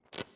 SFX_Harvesting_02_Reverb.wav